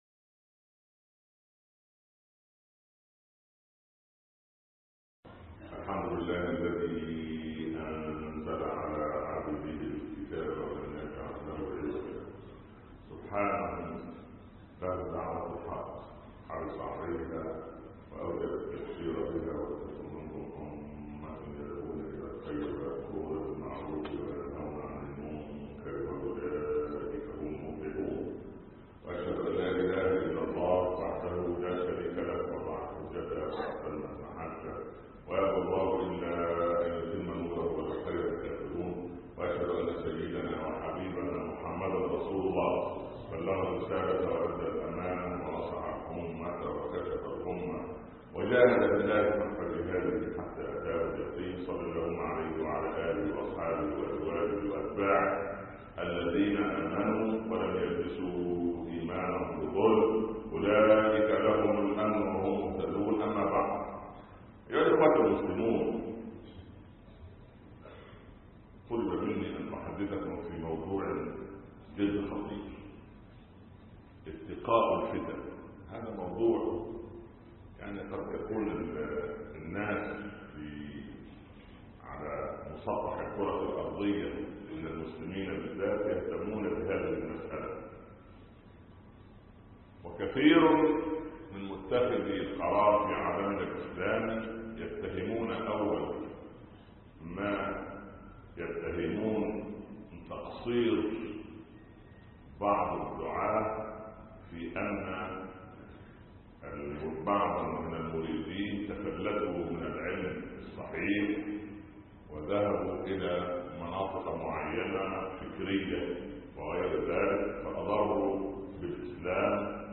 خطب الجمعه